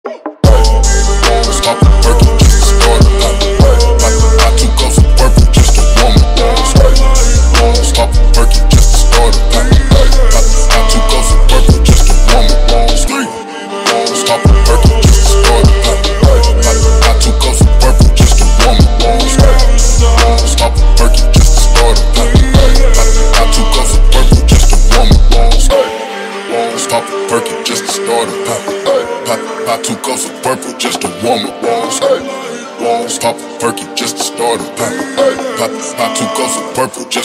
• Качество: 128, Stereo
Хип-хоп
басы
качающие
Стиль: trap